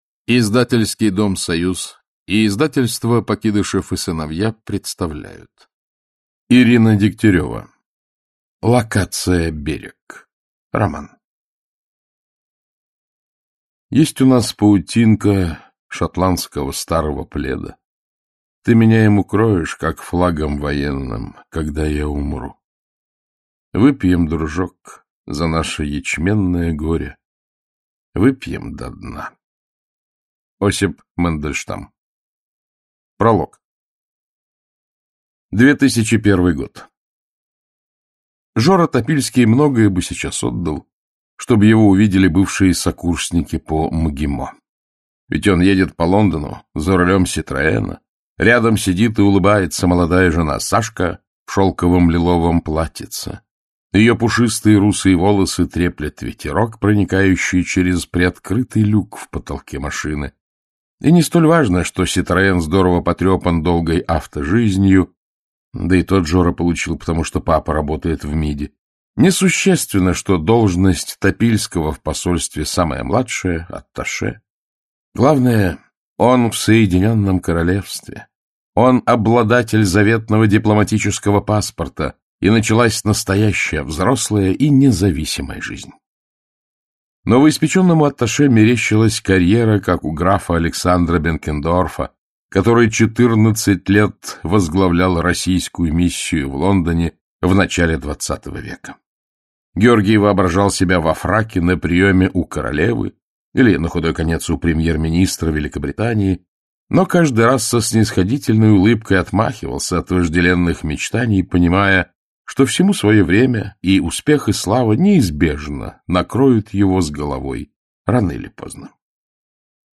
Aудиокнига Локация «Берег» Автор Ирина Дегтярева Читает аудиокнигу Александр Клюквин.